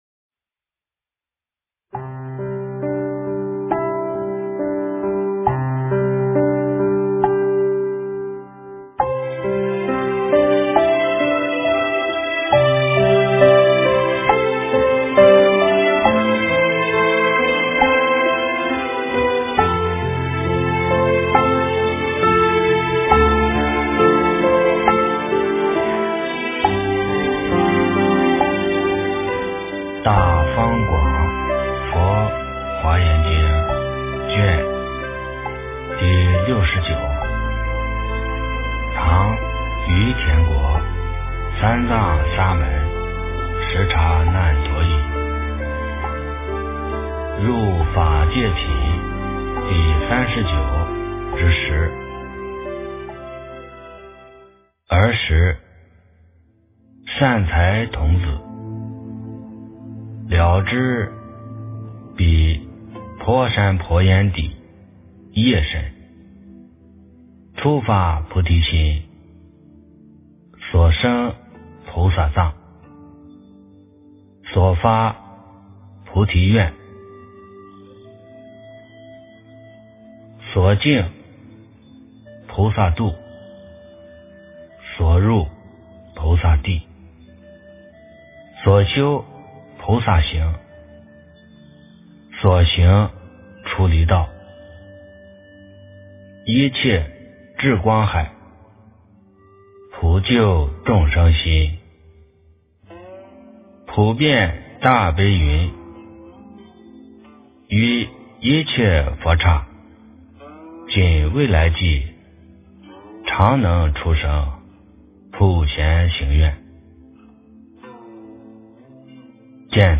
《华严经》69卷 - 诵经 - 云佛论坛